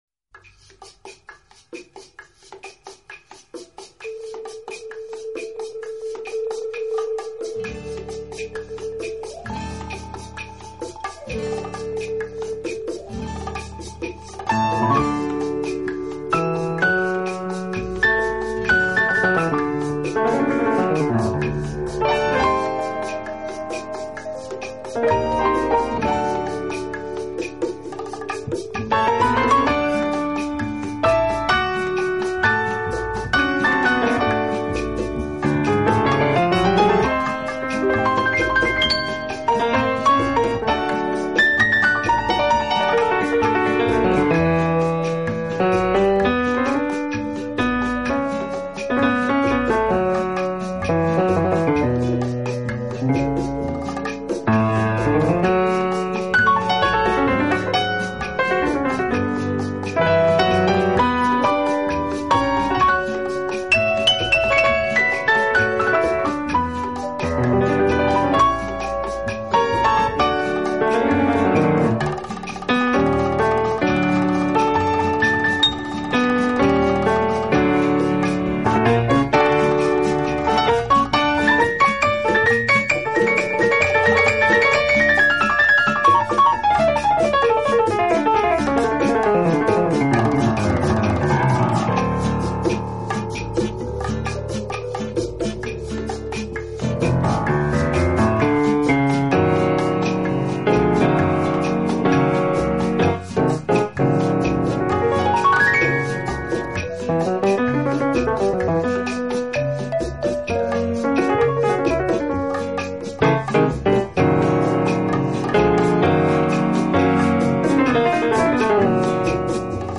【轻音乐专辑】
演奏以轻音乐和舞曲为主。
已是83岁高龄，但他的音乐仍然洋溢着青春的律动。